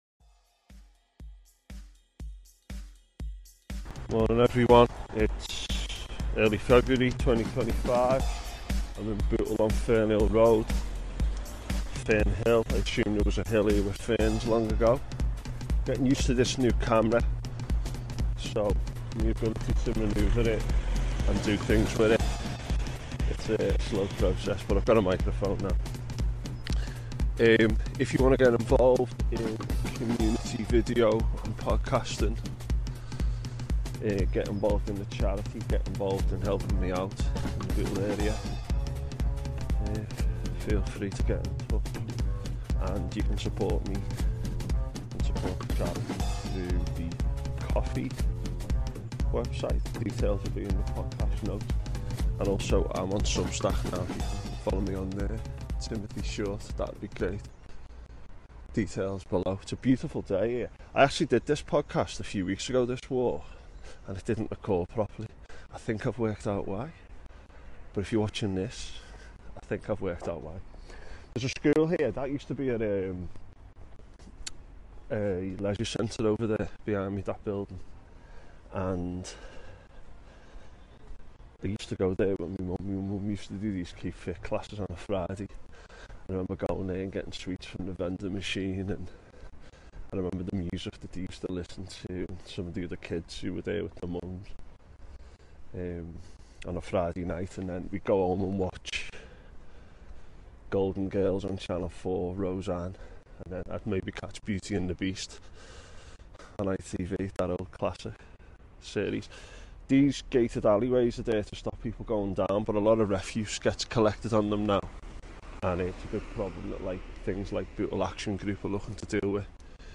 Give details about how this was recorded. Apologies for poor sound in places. Here I take a walk down Upper Fernhill Road, do some history in the Klondyke Estate, and finish on Springwell.